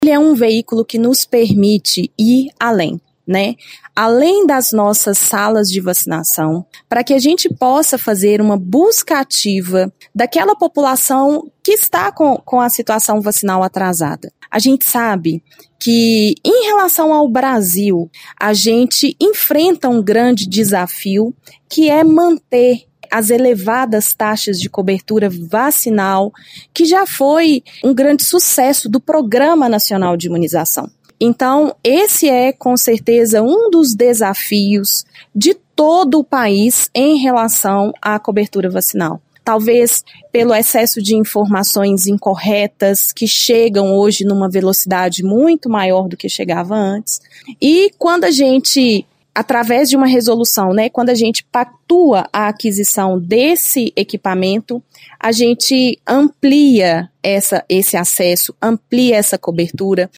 A secretária de Saúde, Ana Clara Meytre, falou conosco: